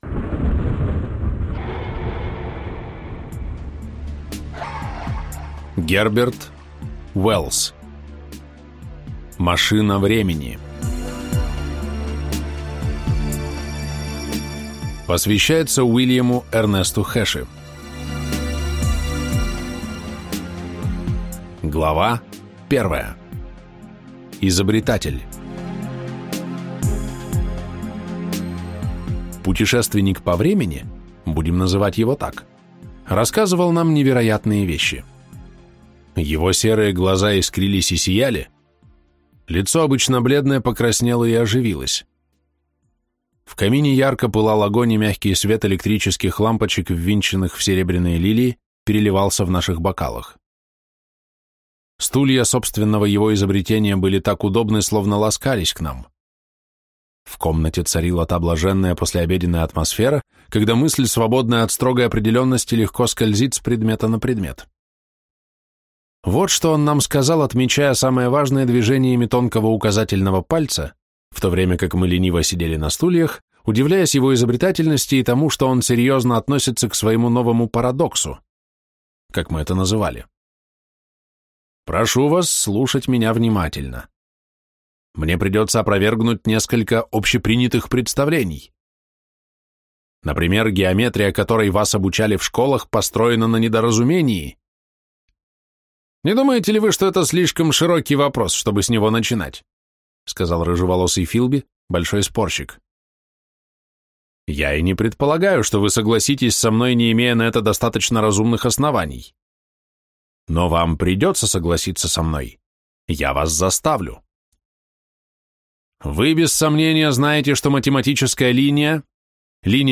Аудиокнига Машина времени. Человек-невидимка | Библиотека аудиокниг